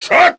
Jin-Vox_Attack3_kr.wav